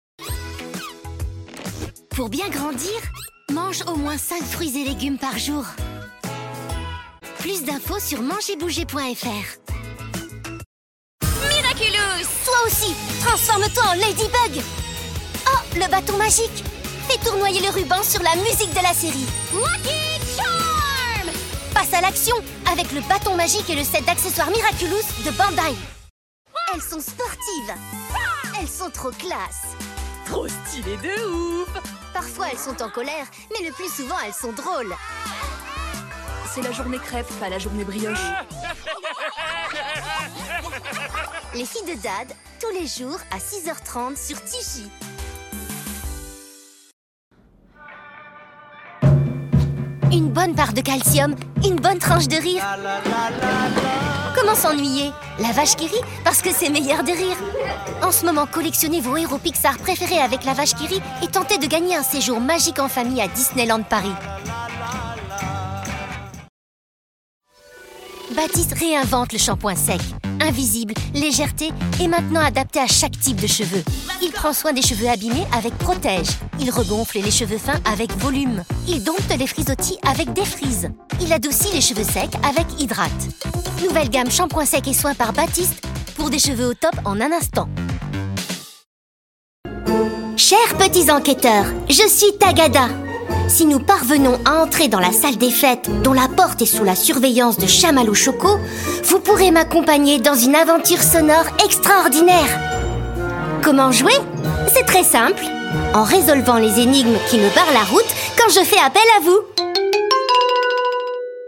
Démo voix
J'ai une voix jeune, légère, cristalline et pétillante...